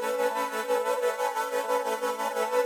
SaS_MovingPad04_90-A.wav